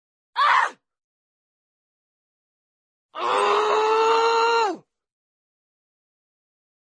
女声愤怒吼声音效免费音频素材下载